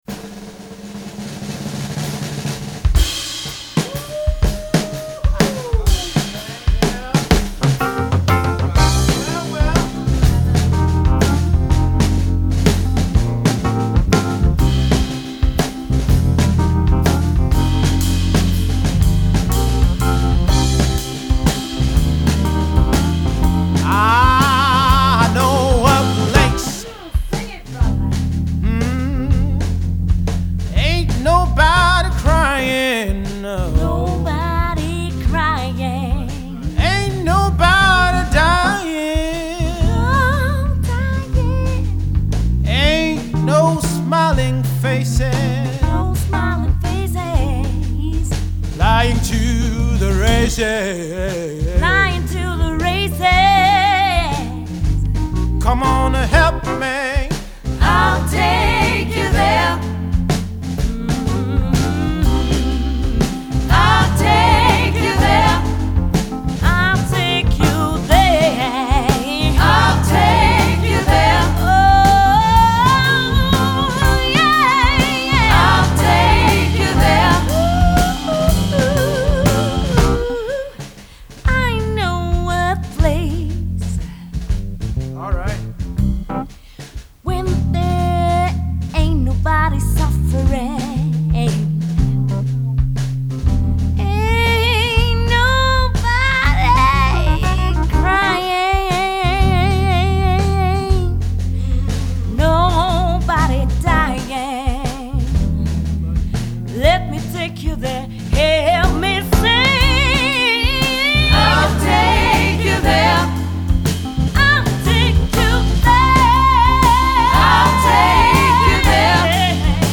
TEMPO : 101
Morceau en C majeur (sensible et 7éme mineure)